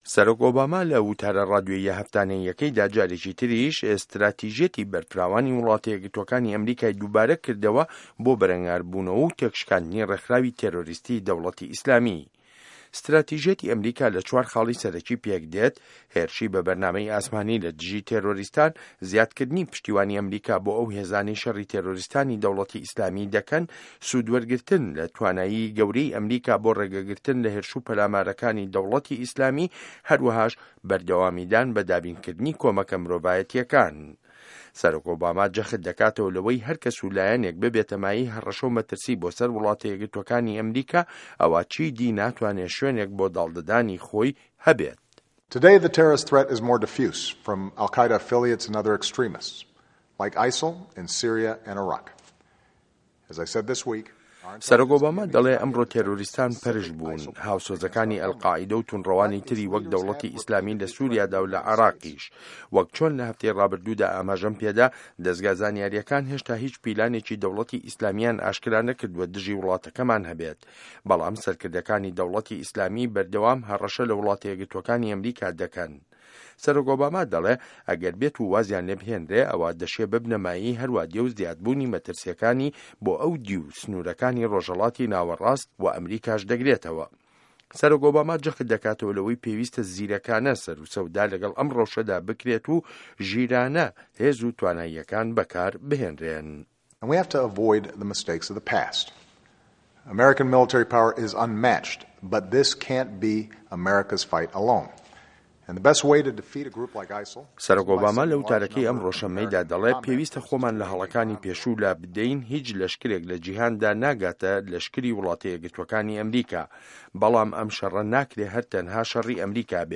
ڕاپۆرت له‌سه‌ر بنچینه‌ی وتاری هه‌فتانه‌ی سه‌رۆک ئۆباما